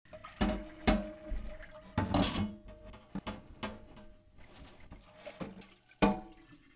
No further effects were added. This sound is correlated with the letter "f" sound.